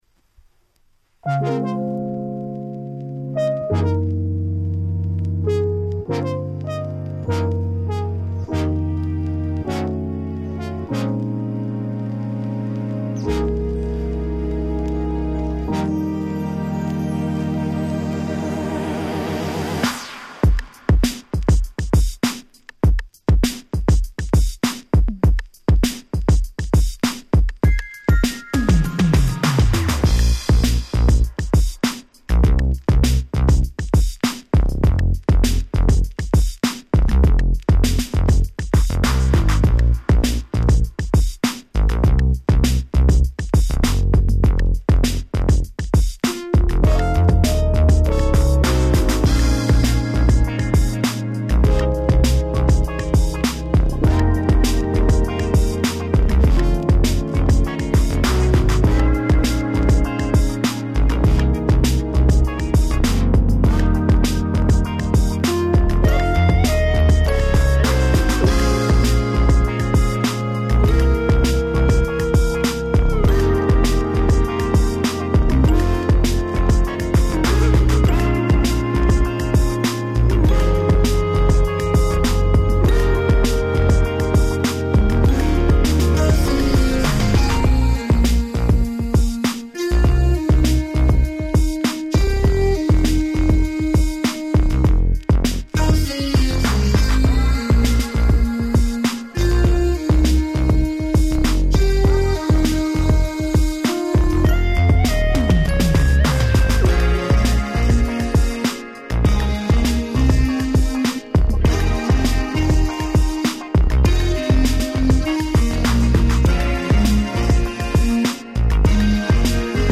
18' Nice R&B/Boogie EP !!